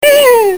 cartoon27.mp3